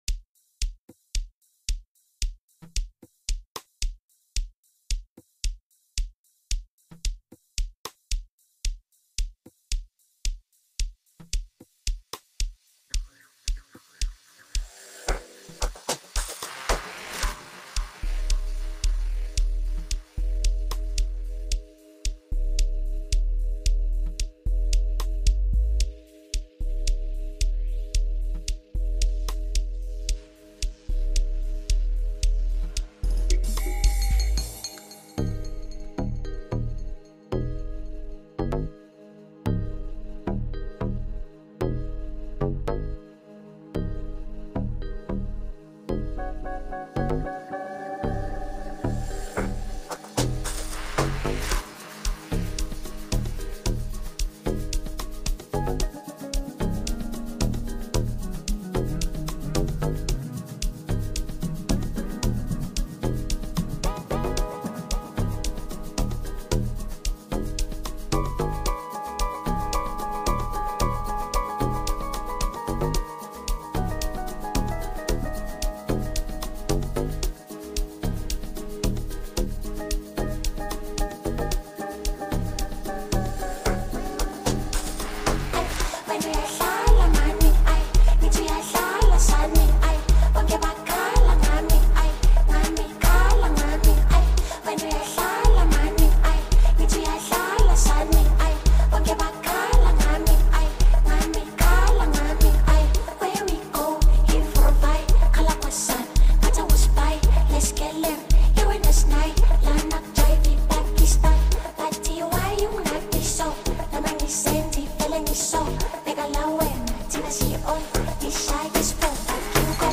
talented South African singer
heartwarming recording